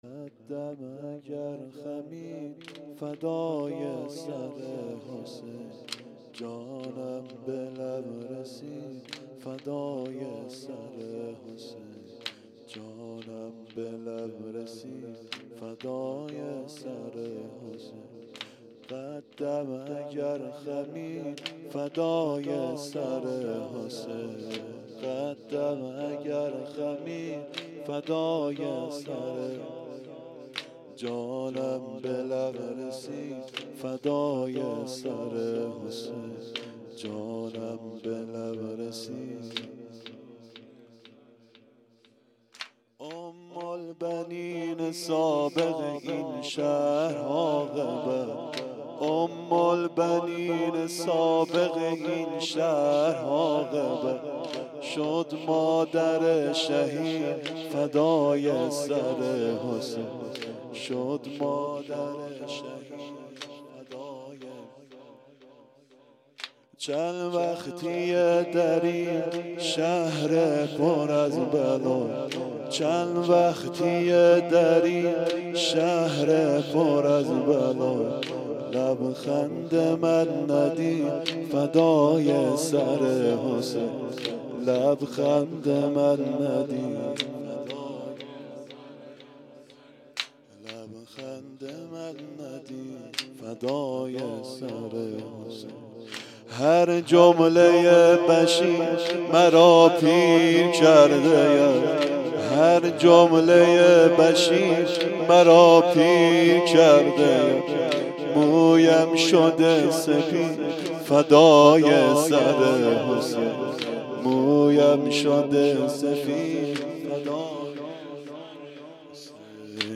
واحد بسیار زیبا